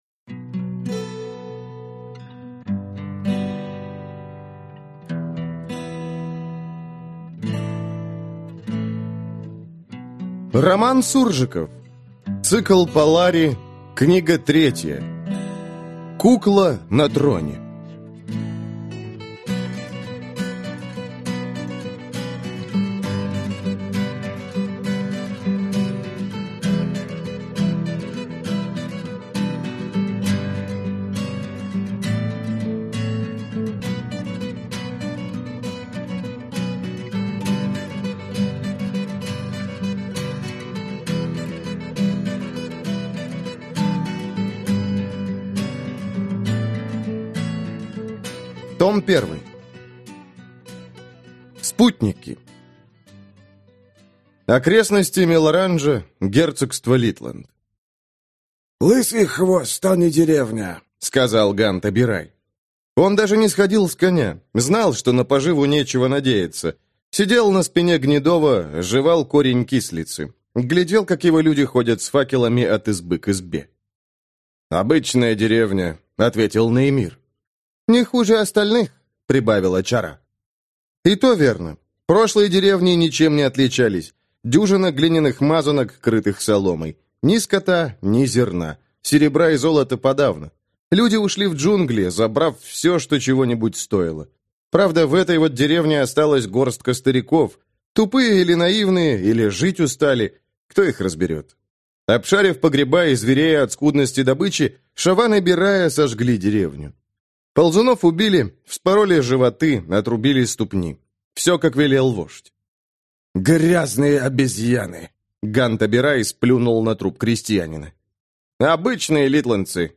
Аудиокнига Кукла на троне. Том I | Библиотека аудиокниг